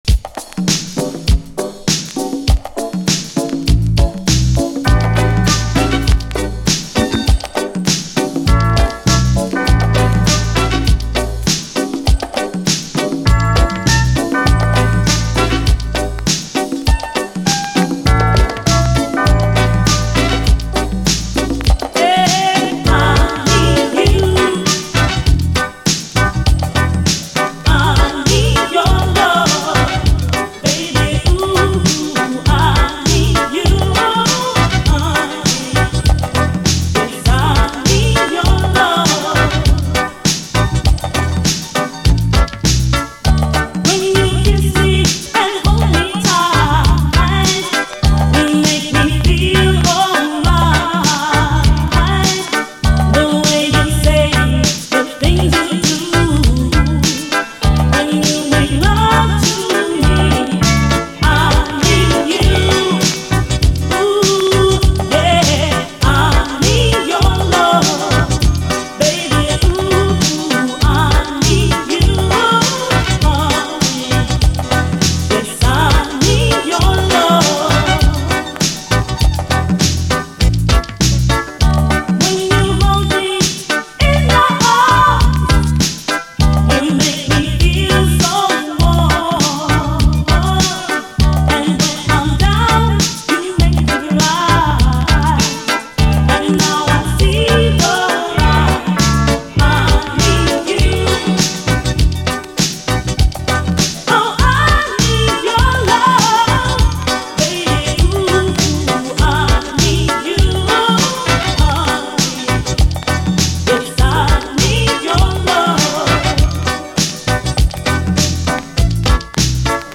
REGGAE
オブスキュアなレアUKラヴァーズ！都会的かつ黒光りするUKストリート・ソウル的なサウンド！
試聴ファイルはこの盤からの録音です/　詳細不明のオブスキュアなレアUKラヴァーズ！